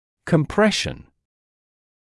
[kəm’preʃn][кэм’прэшн]сжатие, сдавленность, сужение